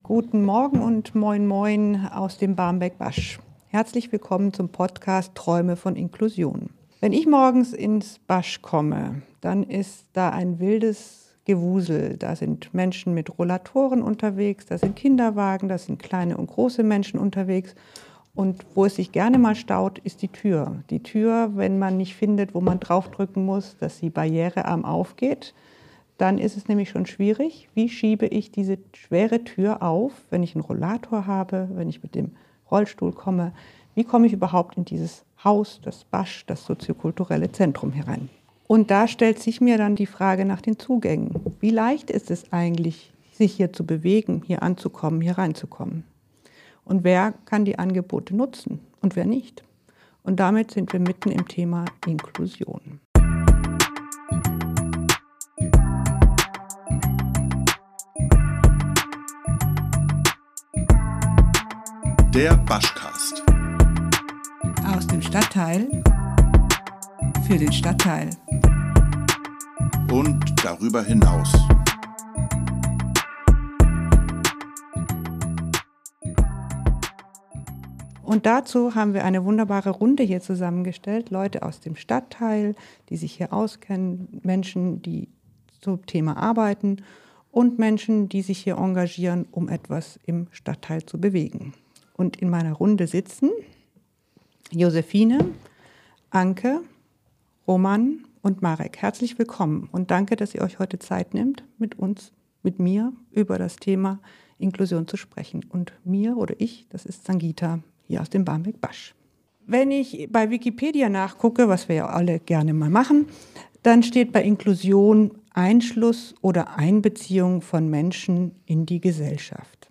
Ein Talk über Inklusion im BASCH und im Stadtteil.